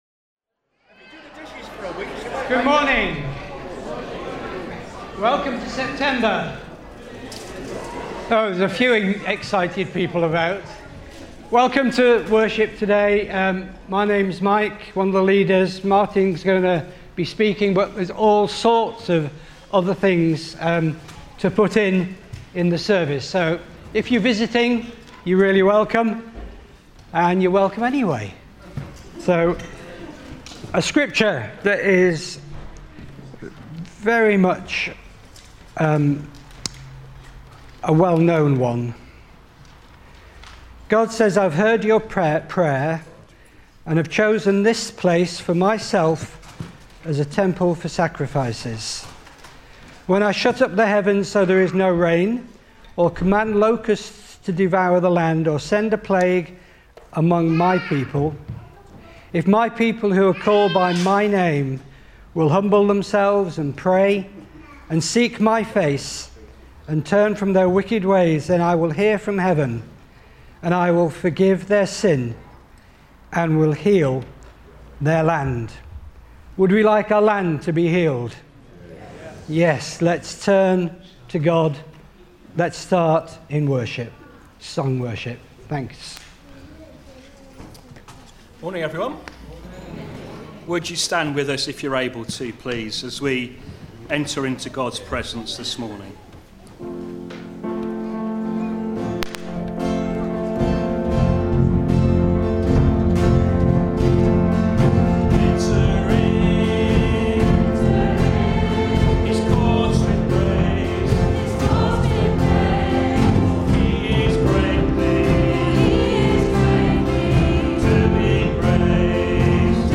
7 September 2025 – Morning Service
Service Type: Morning Service